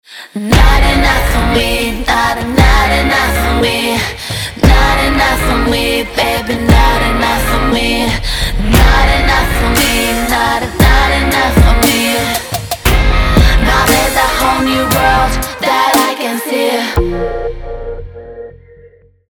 • Качество: 320, Stereo
поп
громкие
женский голос
dance